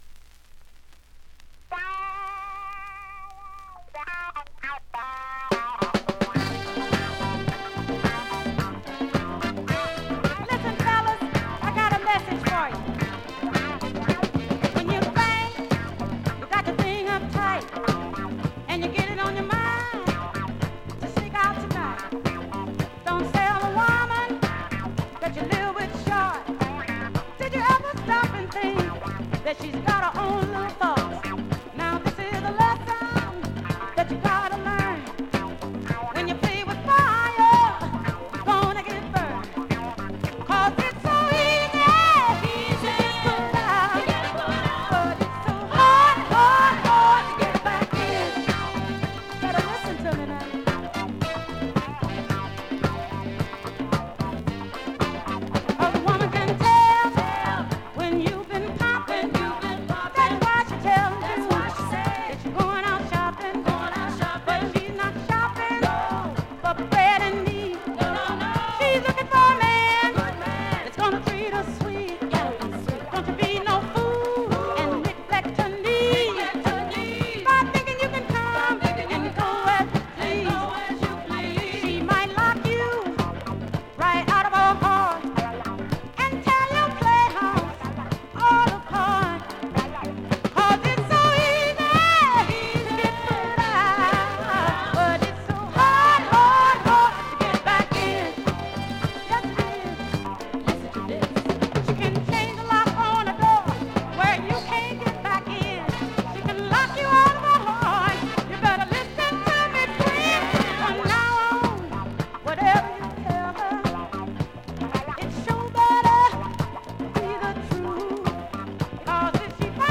サンプリング・ライクなNICE SOUL